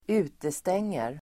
Uttal: [²'u:testeng:er]